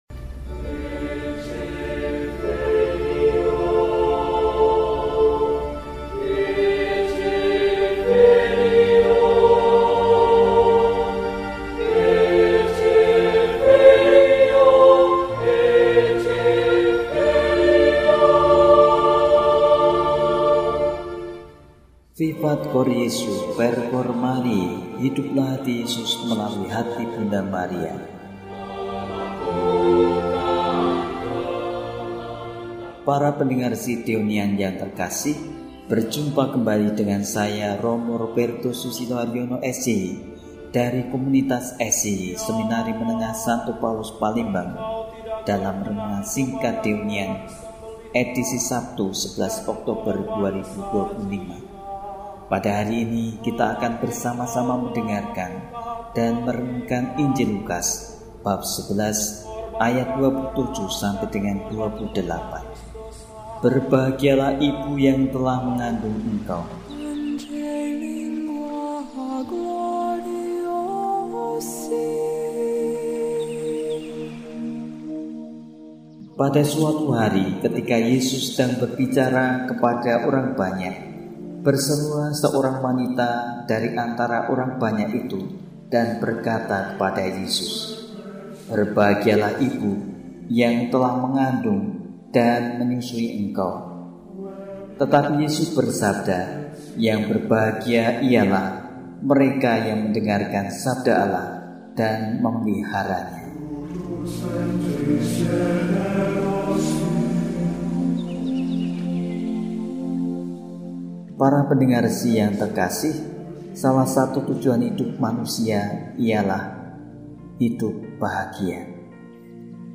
Sabtu, 11 Oktober 2025 – Hari Biasa Pekan XXVII – RESI (Renungan Singkat) DEHONIAN